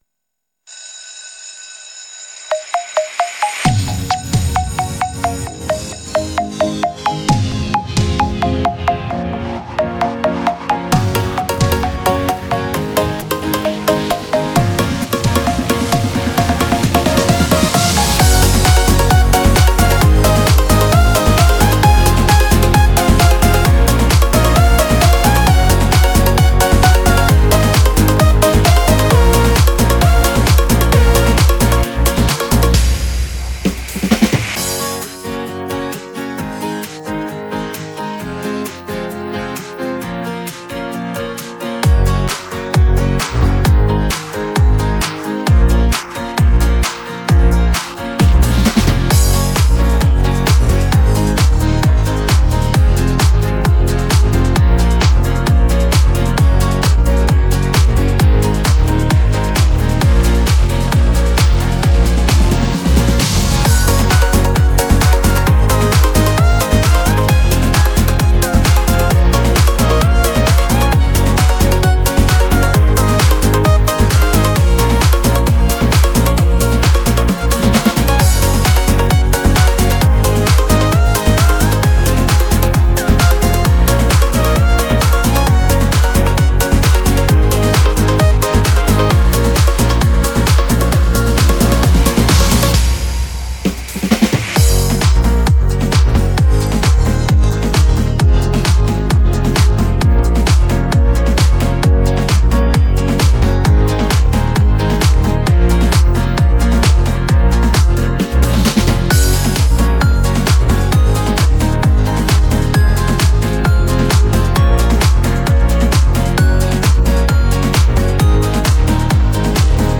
Песни для 1 класса